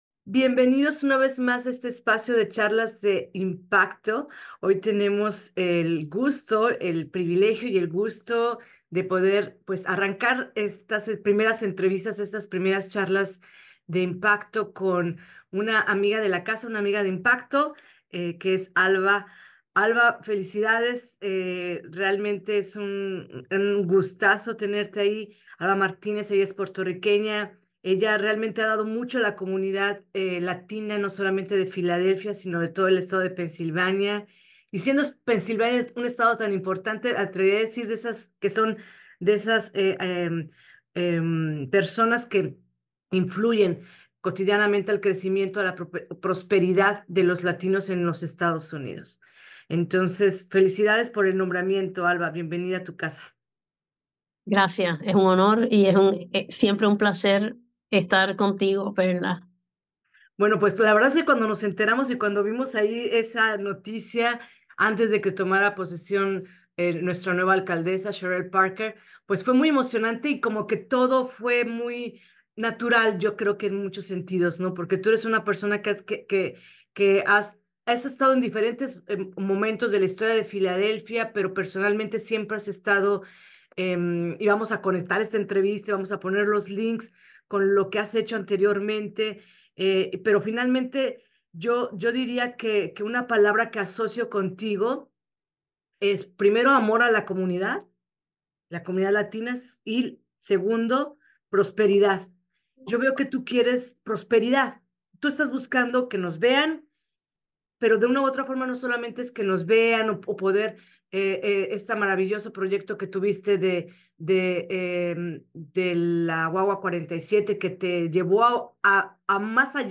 entrevista-final.mp3